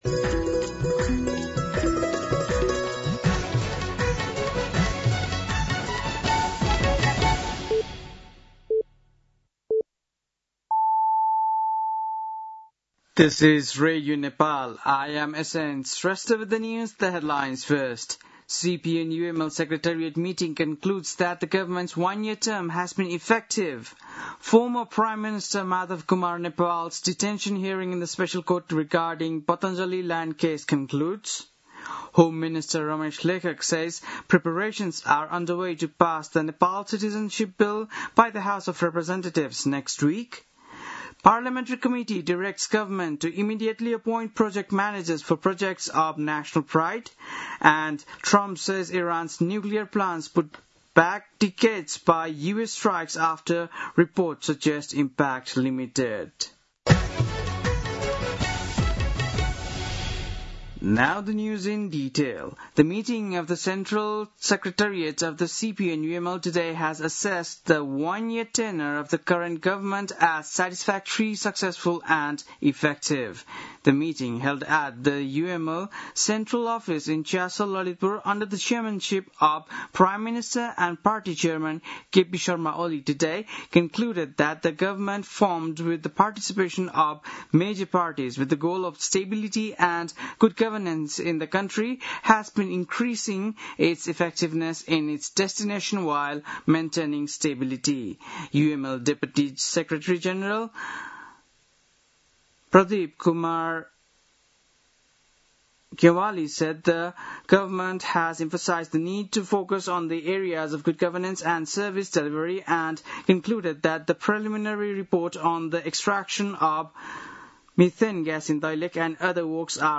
बेलुकी ८ बजेको अङ्ग्रेजी समाचार : ११ असार , २०८२
8-pm-news-3-11.mp3